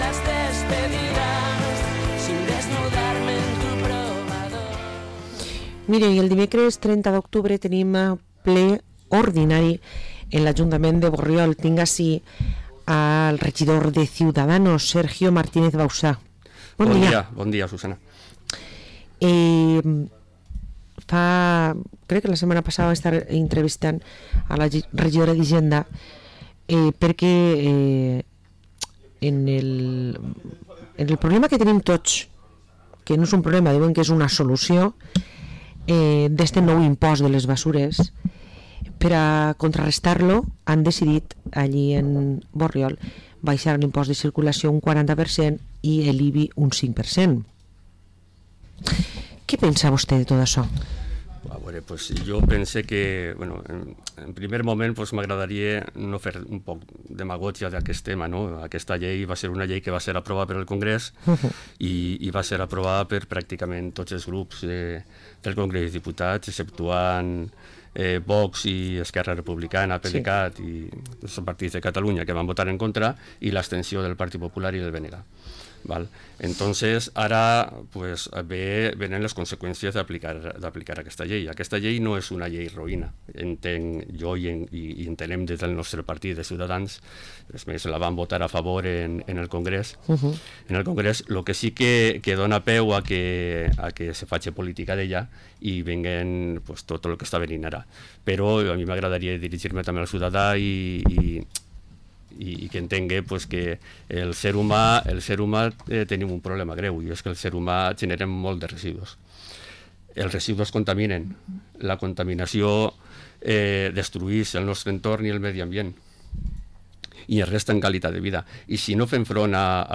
Parlem amb Sergio Martínez Bausá, regidor de Ciudadanos a l´Ajuntament de Borriol